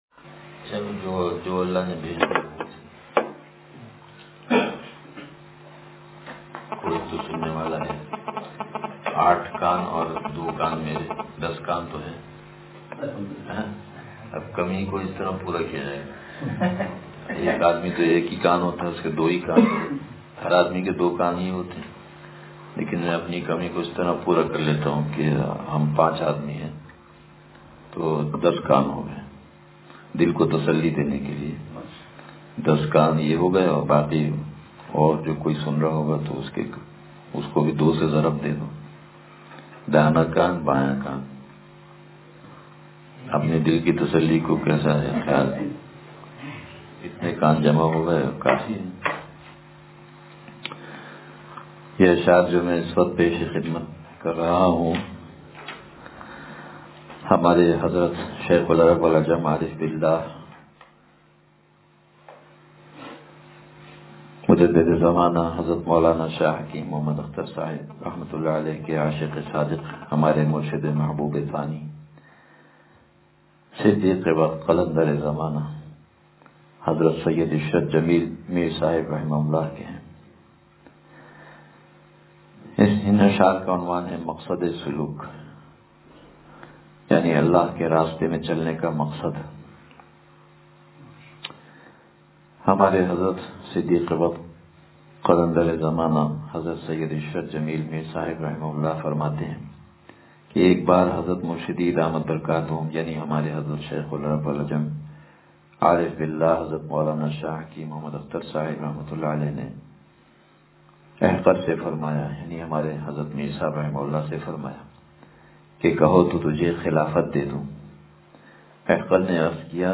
مقصدِ سلوک – اتوار مجلس